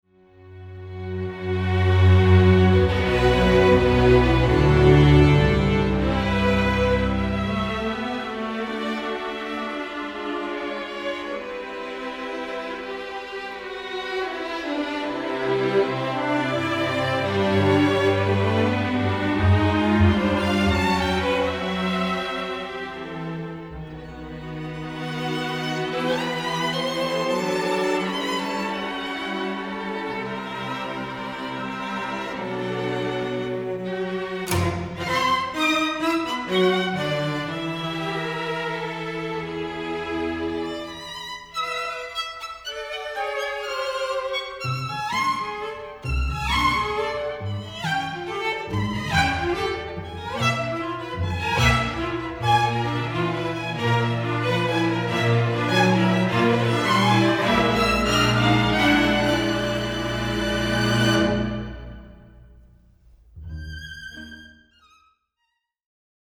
Instrumentation: String Orchestra
Ensemble: String Orchestra